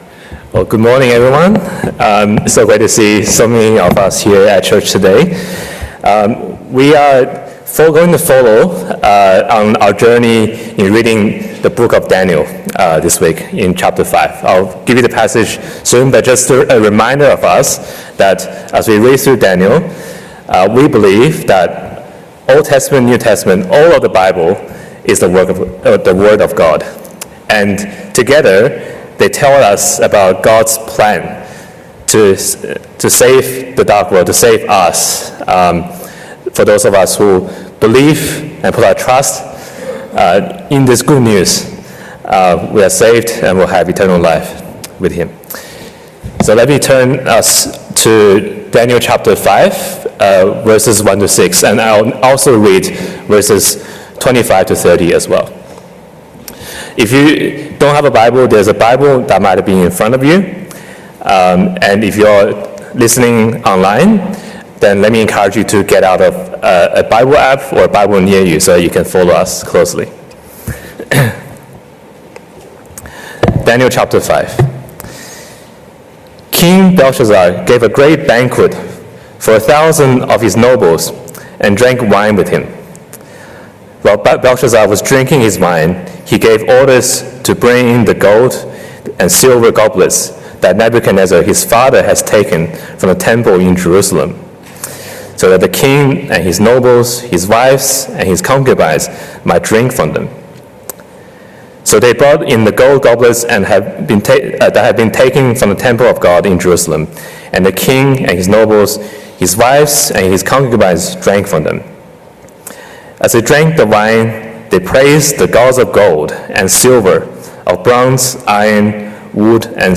Series: Daniel - God's providence in history Passage: Daniel 5:1-30 Service Type: 10:45 English